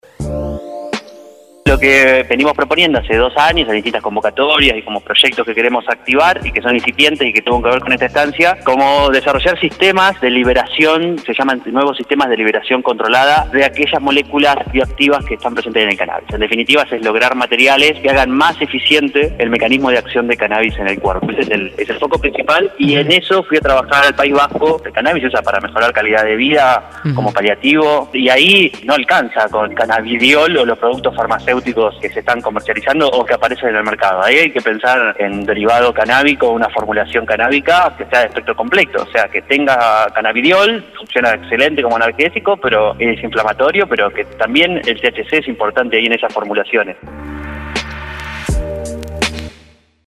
Entrevista en Radio 10.